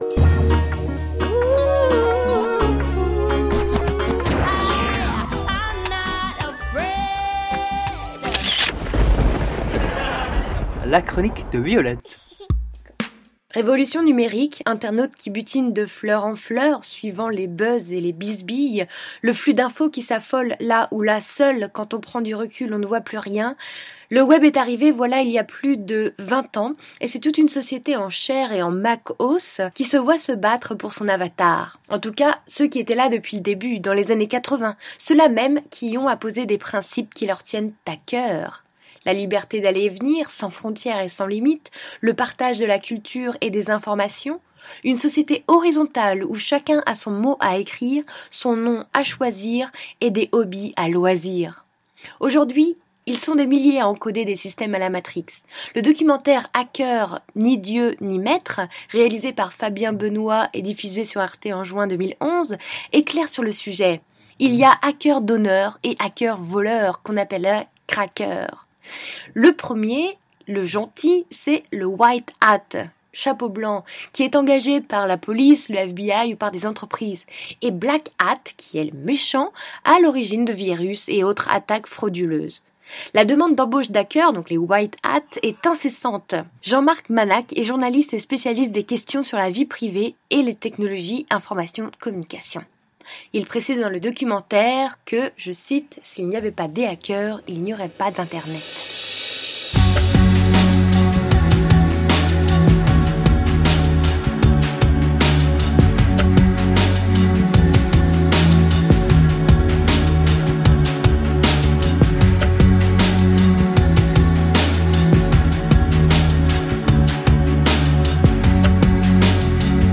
Illustration sonore: